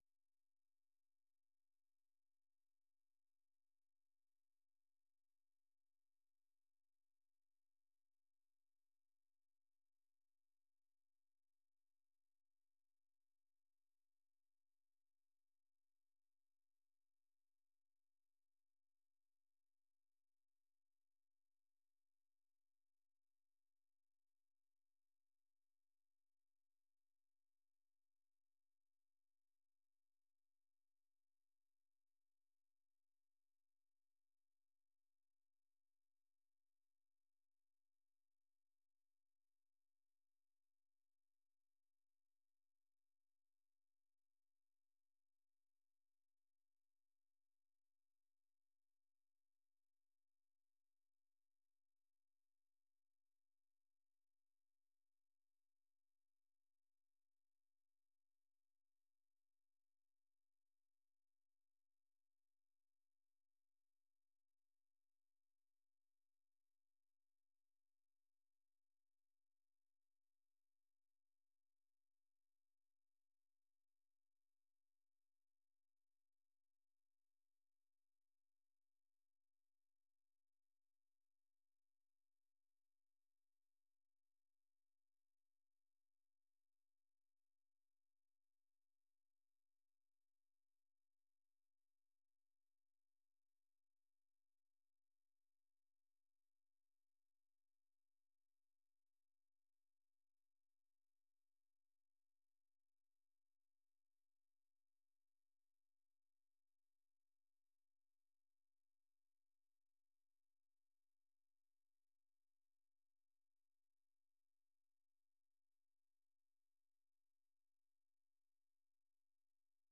The URL has been copied to your clipboard 페이스북으로 공유하기 트위터로 공유하기 No media source currently available 0:00 0:59:58 0:00 생방송 여기는 워싱턴입니다 생방송 여기는 워싱턴입니다 공유 생방송 여기는 워싱턴입니다 share 세계 뉴스와 함께 미국의 모든 것을 소개하는 '생방송 여기는 워싱턴입니다', 아침 방송입니다.